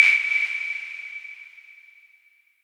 Perc  (2).aif